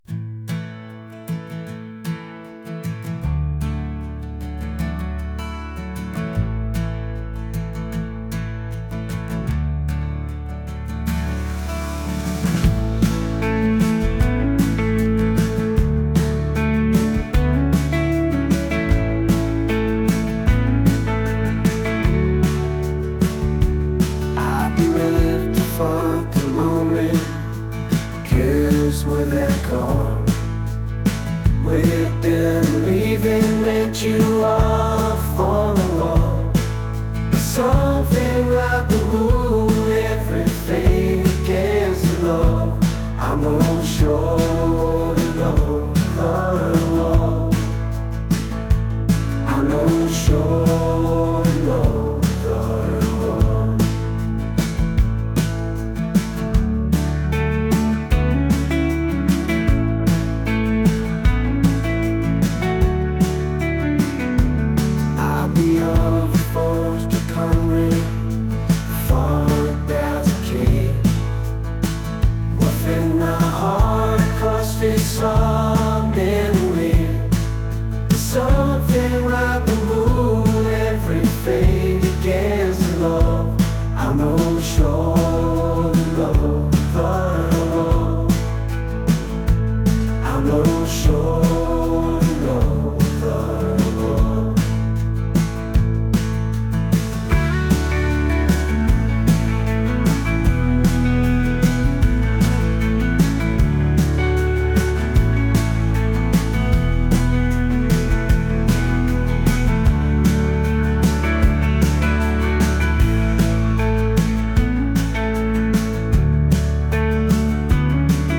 acoustic | folk | soulful